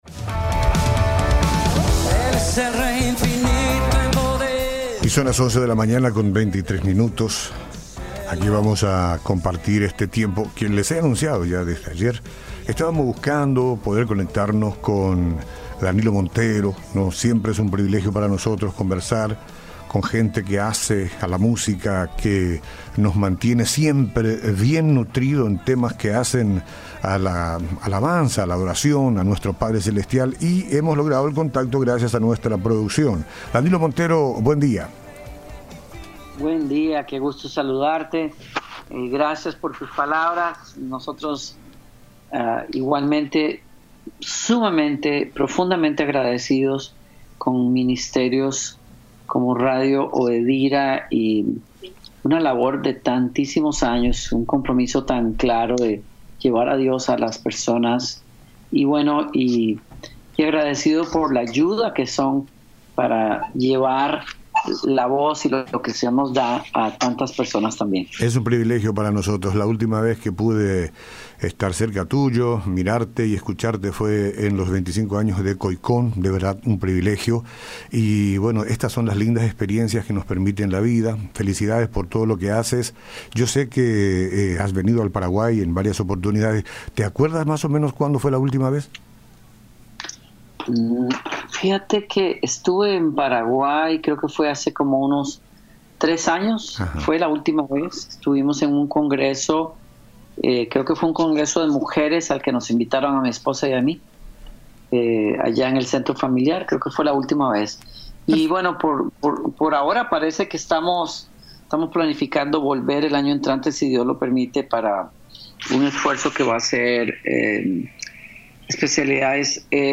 en un alegre y contagioso pop latino con arreglos caribeños